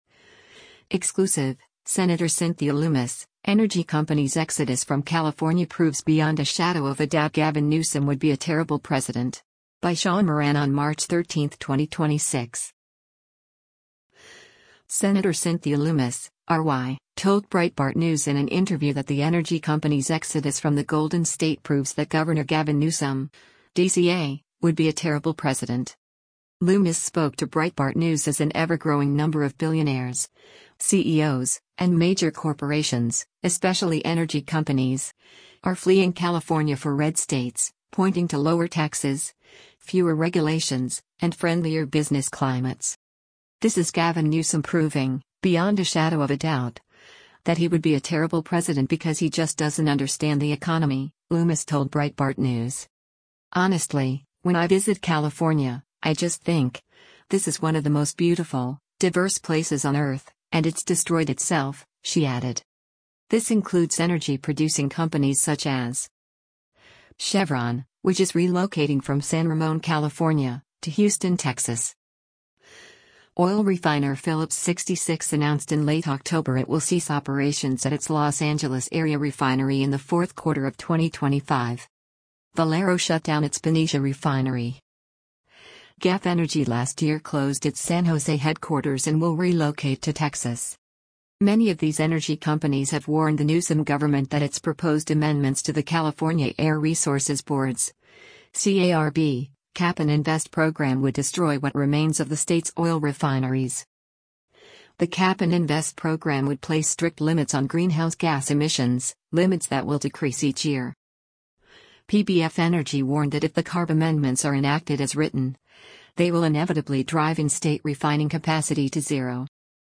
Sen. Cynthia Lummis (R-WY) told Breitbart News in an interview that the energy companies’ exodus from the Golden State proves that Gov. Gavin Newsom (D-CA) would be a terrible president.